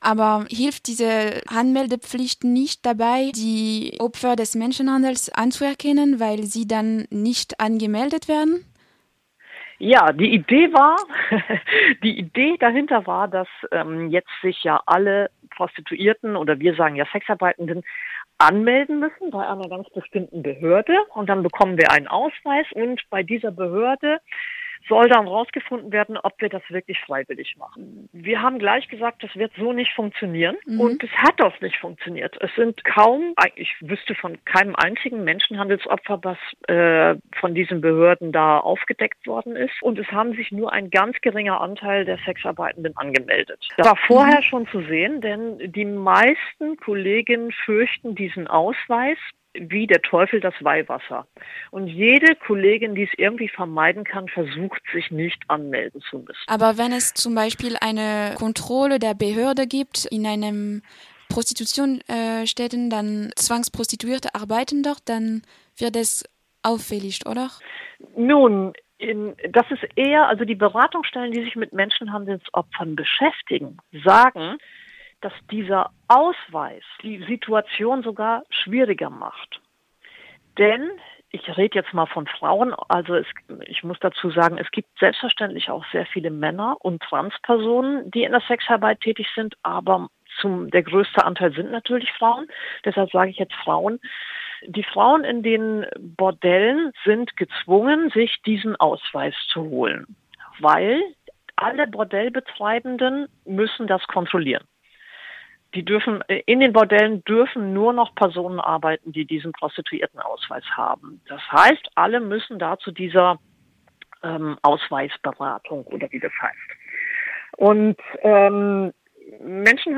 Wir haben uns entschieden, die Sexarbeiter_innen selbst zu Wort kommen zu lassen.